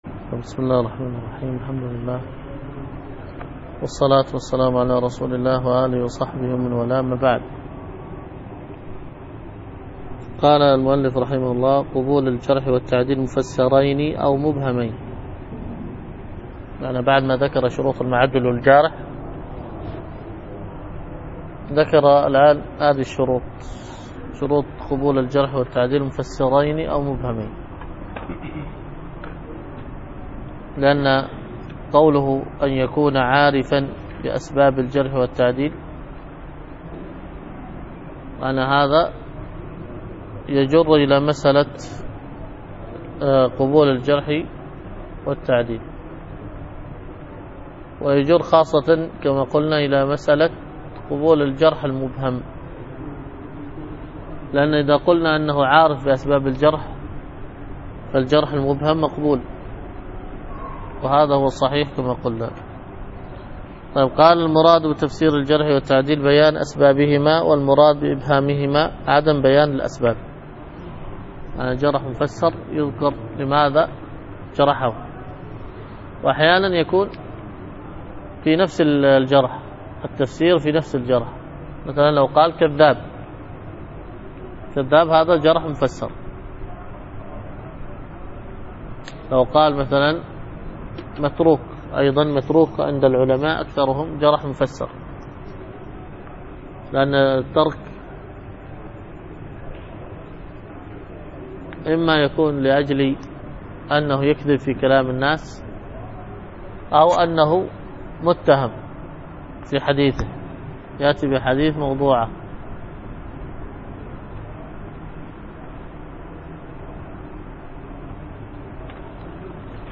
الدروس الحديث وعلومه